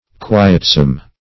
Quietsome \Qui"et*some\
quietsome.mp3